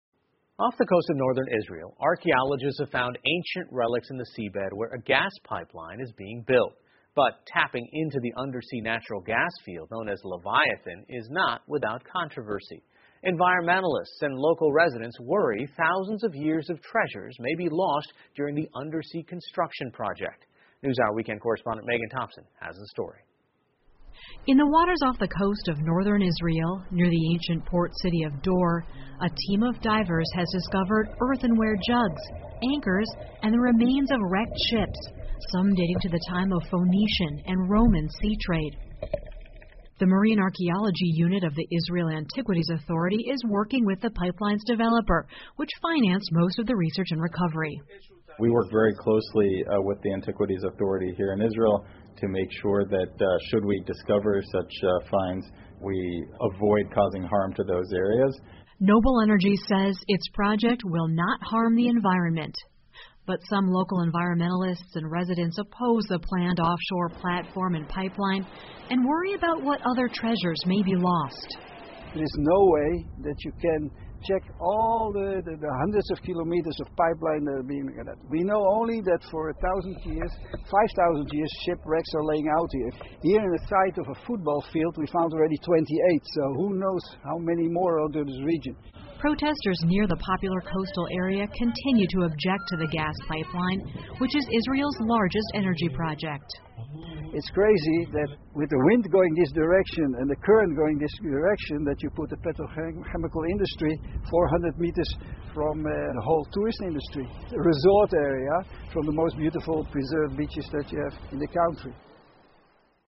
PBS高端访谈:古代遗址和油气管道的争夺 听力文件下载—在线英语听力室